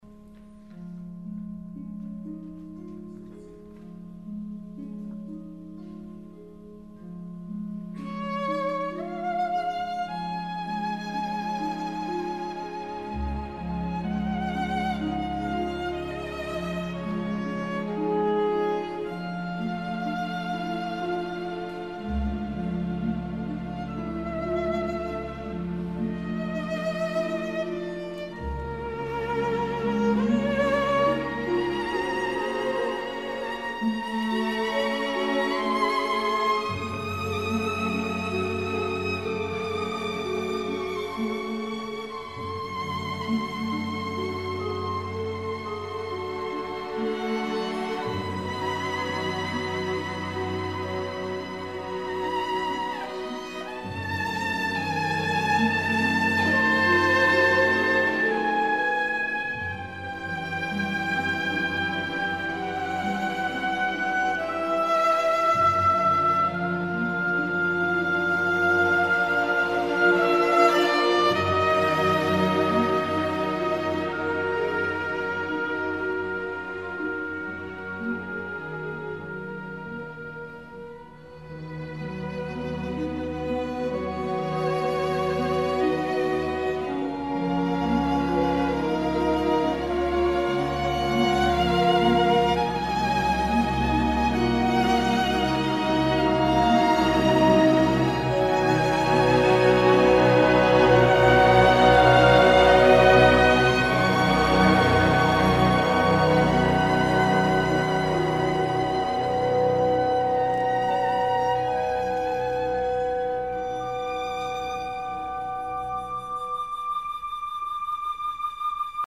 for Viola and Symphony Orchestra
Viola